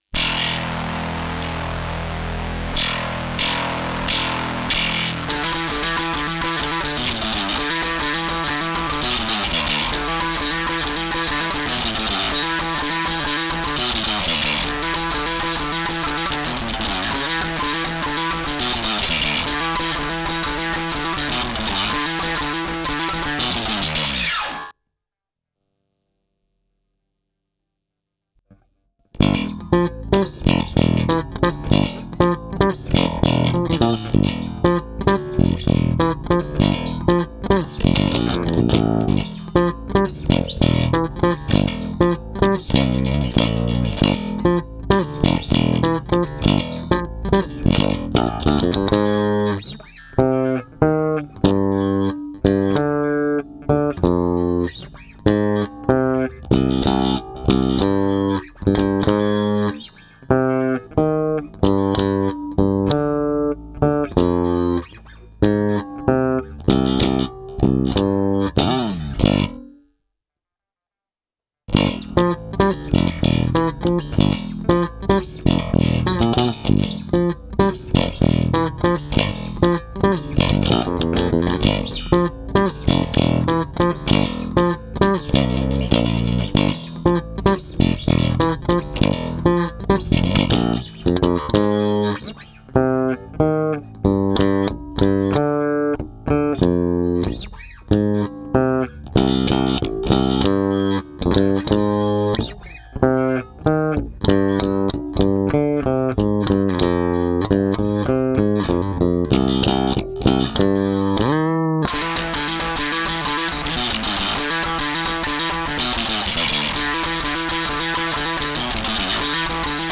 (bass only)